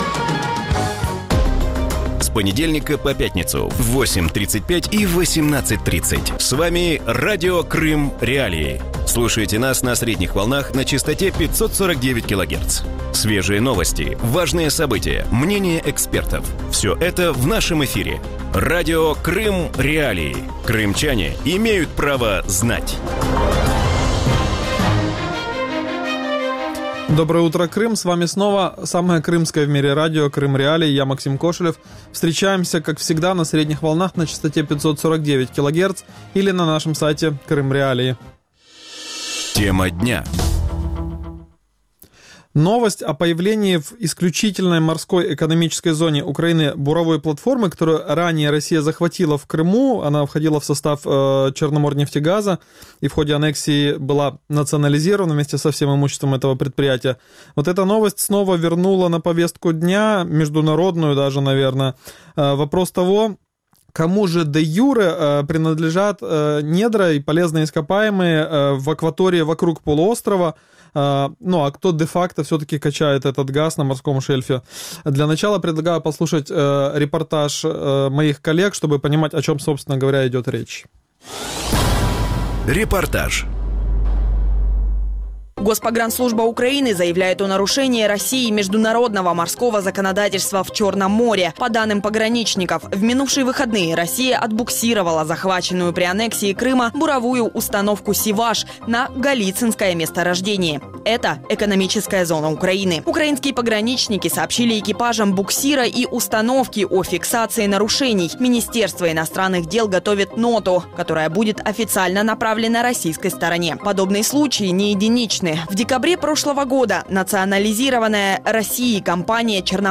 Вранці в ефірі Радіо Крим.Реалії говорять про конфлікт довколо надр чорноморського шельфу після анексії Криму. В той час як Росія спокійно продовжує розробляти поклади, що де-юре належять Україні, у Києві обіцяють оскаржити ці дії у міжнародних судах. Чи мають ці судові справи які-небудь перспективи?